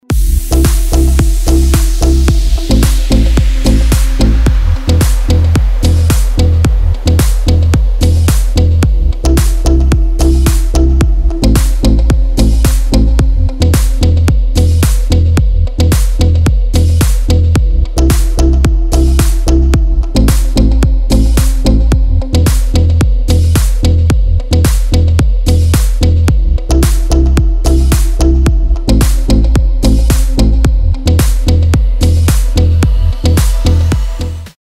• Качество: 256, Stereo
deep house
спокойные
без слов
Melodic